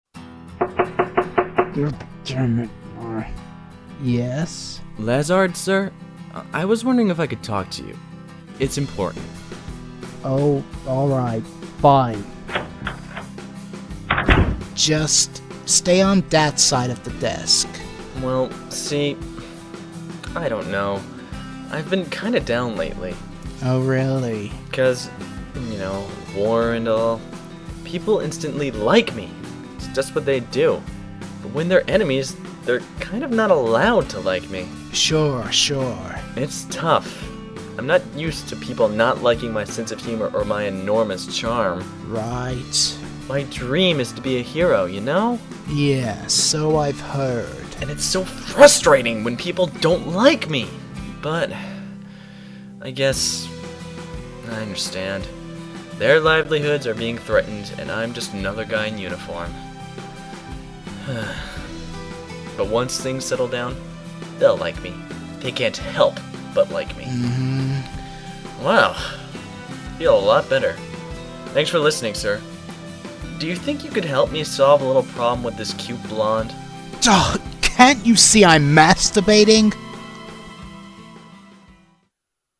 Audio Dramas